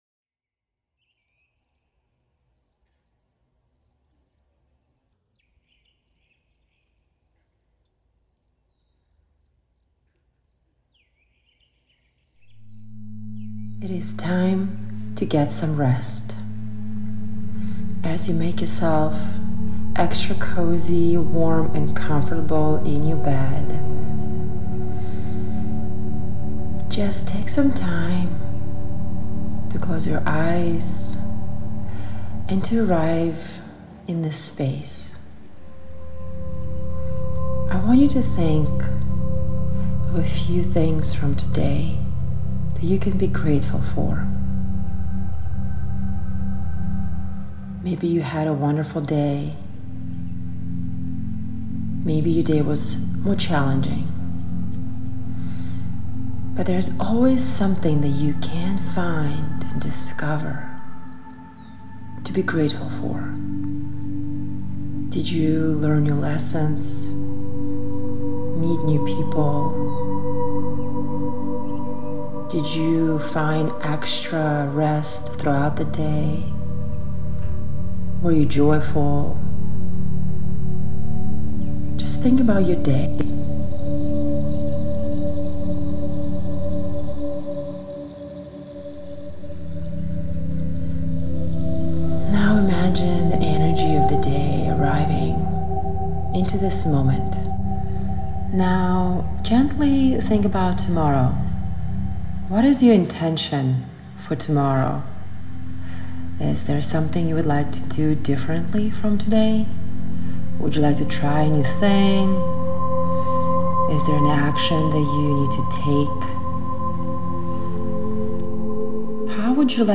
Once recorded, our musicians infuse the mini with unique, powerful music, capturing its essence.